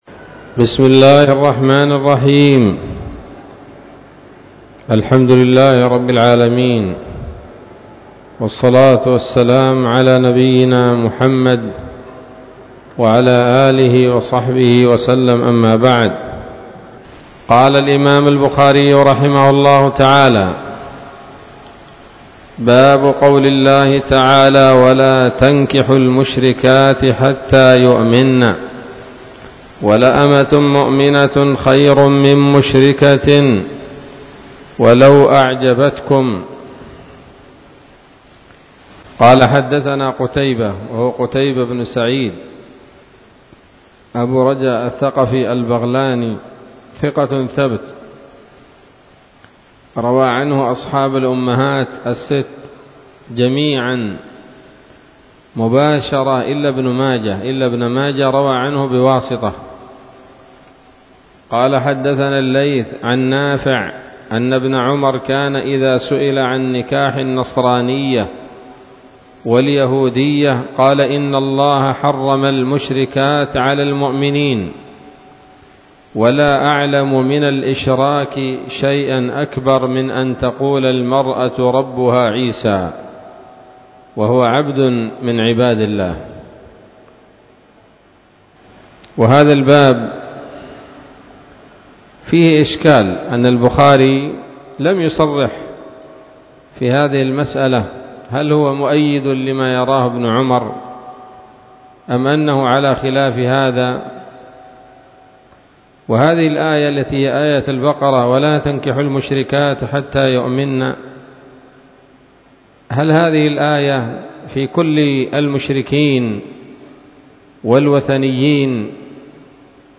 الدرس الرابع عشر من كتاب الطلاق من صحيح الإمام البخاري